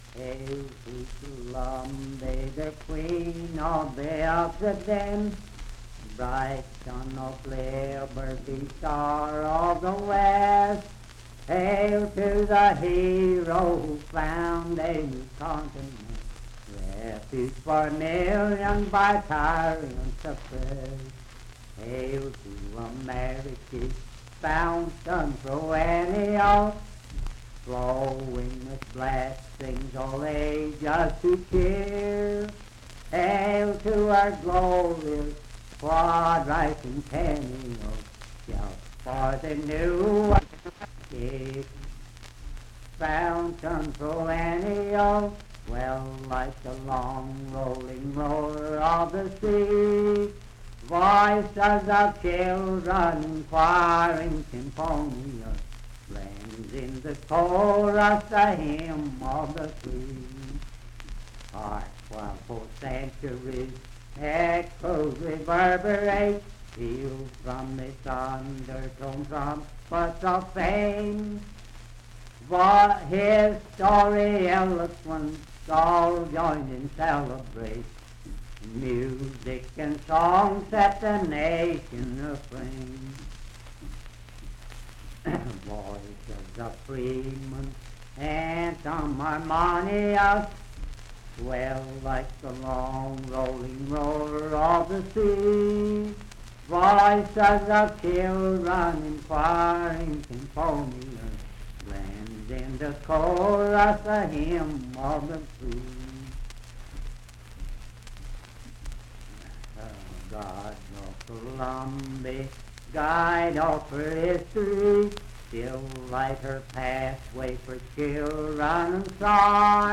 Unaccompanied vocal music and folktales
Political, National, and Historical Songs
Voice (sung)
Wood County (W. Va.), Parkersburg (W. Va.)